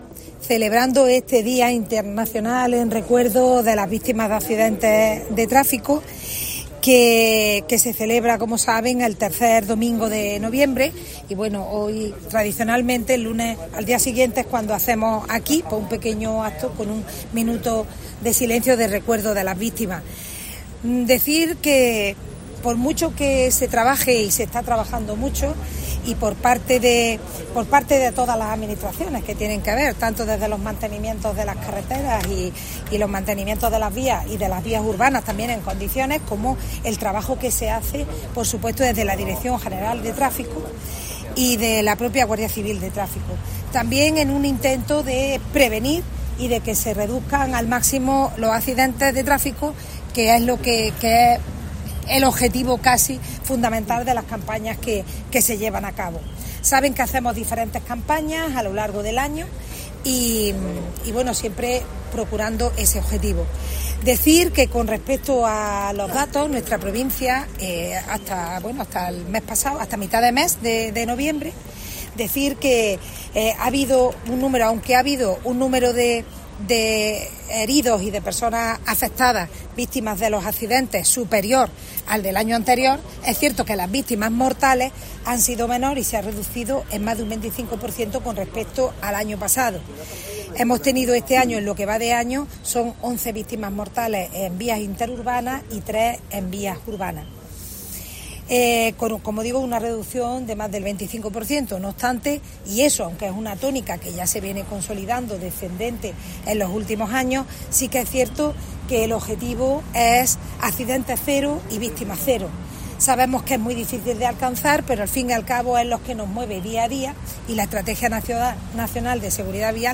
Catalina Madueño hace balance de los accidentes de tráfico en la provincia
Así lo ha señalado durante el acto institucional organizado por la Jefatura Provincial de la Dirección General de Tráfico (DGT) con motivo del Día Mundial en Recuerdo de las Víctimas de Accidentes de Tráfico.